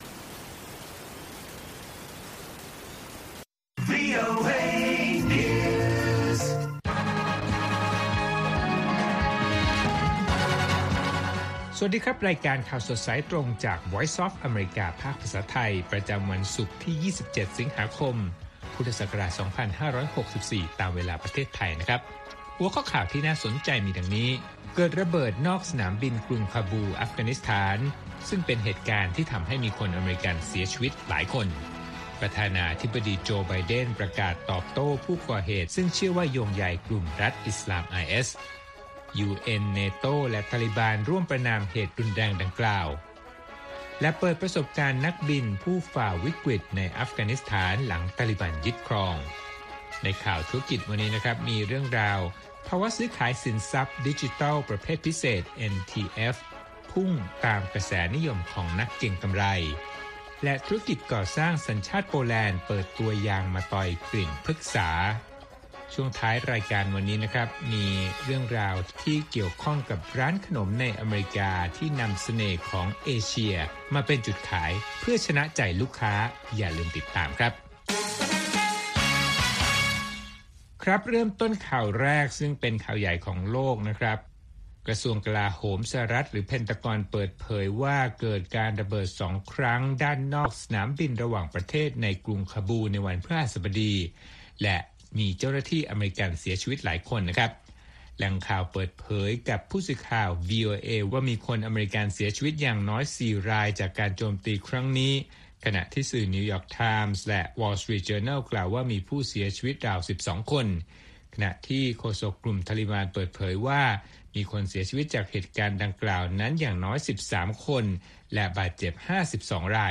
ข่าวสดสายตรงจากวีโอเอ ภาคภาษาไทย ประจำวันศุกร์ที่ 27 สิงหาคม 2564 ตามเวลาประเทศไทย